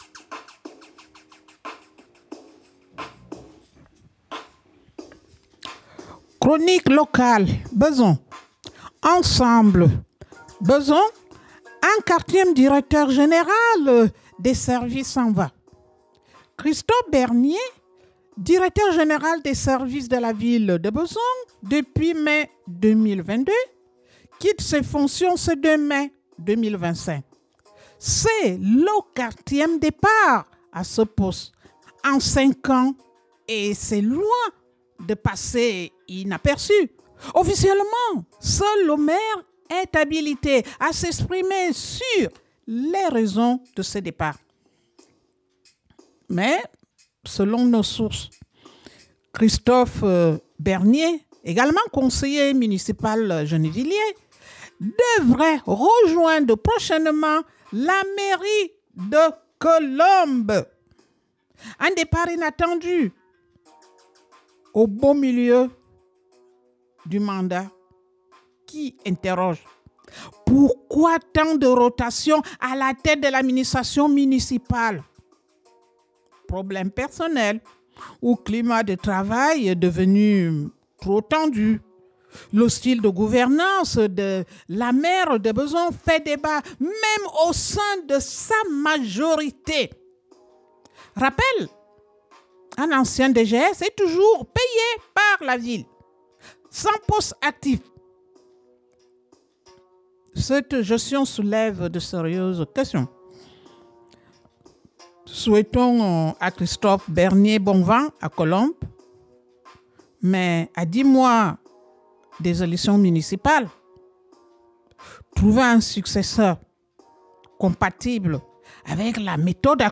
CHRONIQUE LOCALE – BEZONS